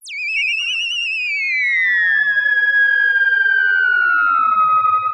Theremin_Swoop_13.wav